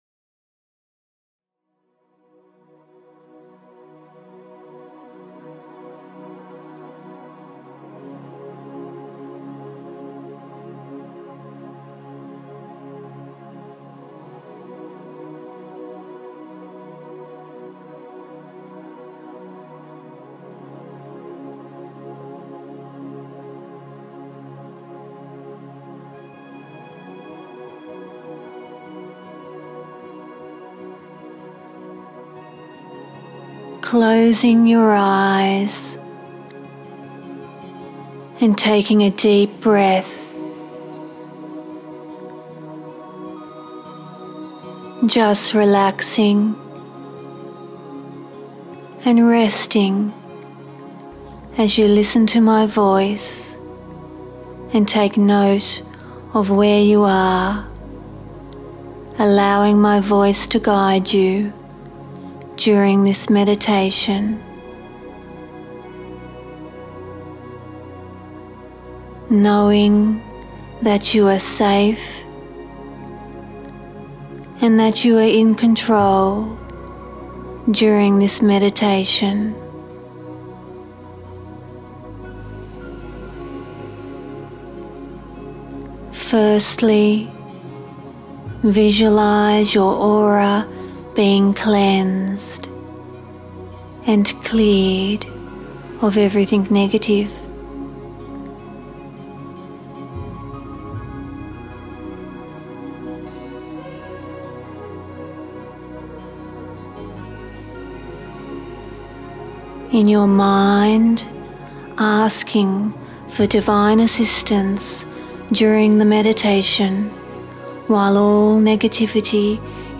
Meditations